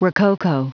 Prononciation du mot rococo en anglais (fichier audio)
Prononciation du mot : rococo